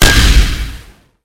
medium_hit.ogg